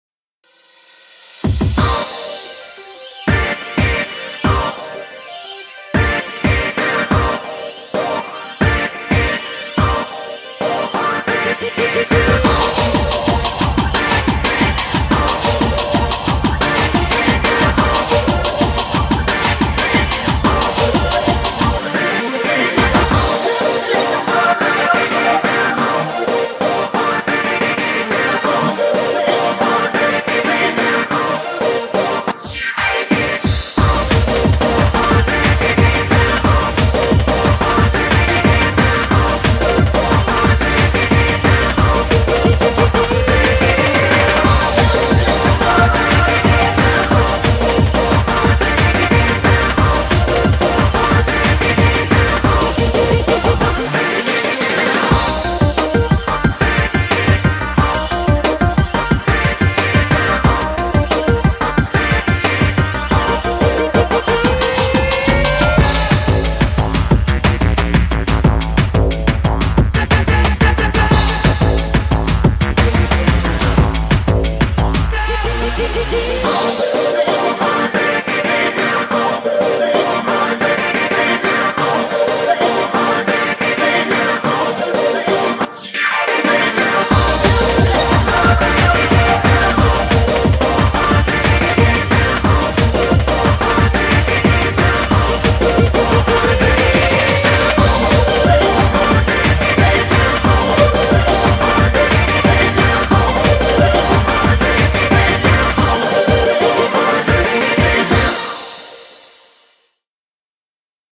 BPM90-180